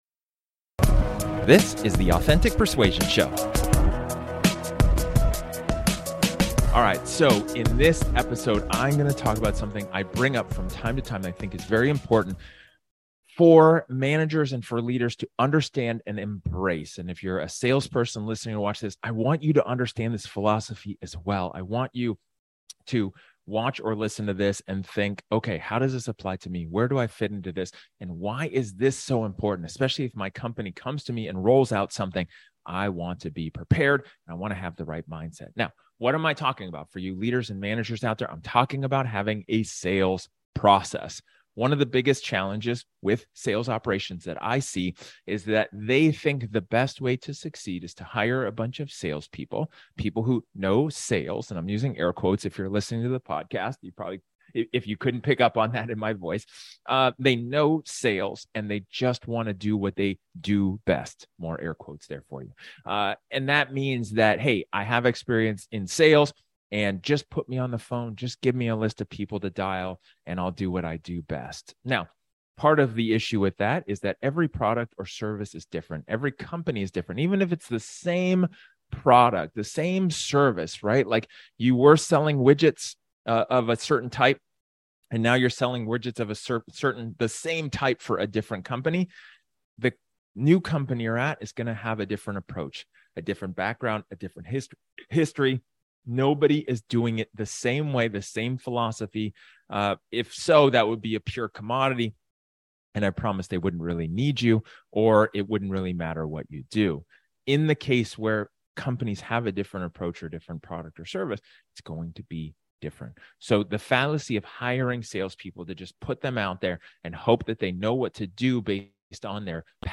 In this solo episode, I talk about the importance of having a sales process.